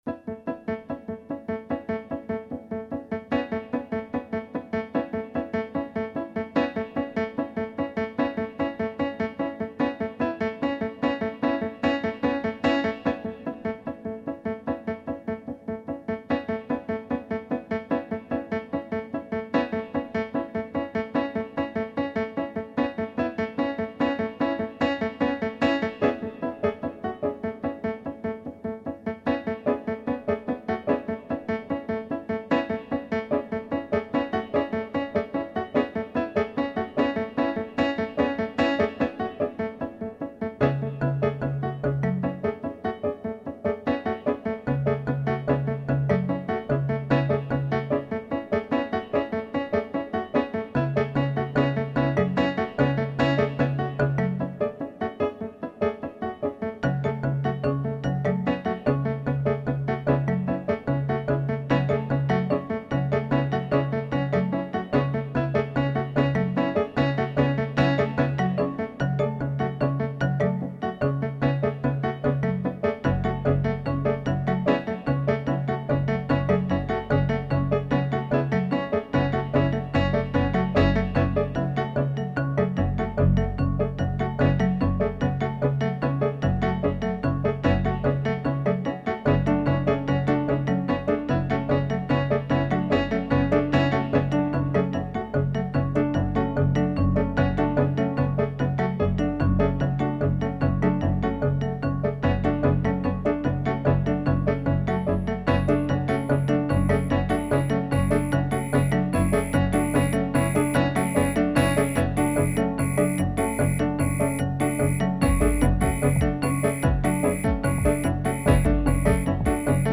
The third element to enter is the bass marimba.